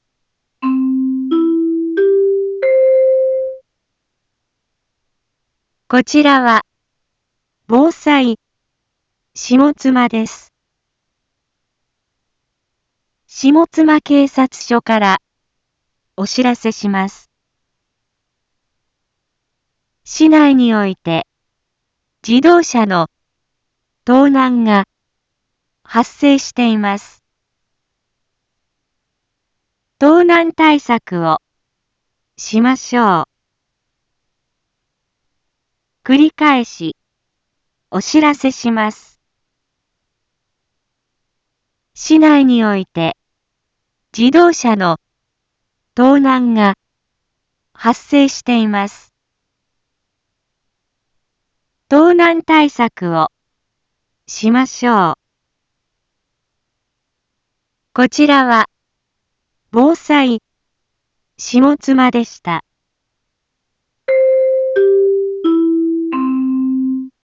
一般放送情報
Back Home 一般放送情報 音声放送 再生 一般放送情報 登録日時：2021-10-12 12:31:11 タイトル：自動車盗難への警戒について インフォメーション：こちらは、防災下妻です。